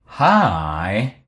Man says Hi
Hi.mp3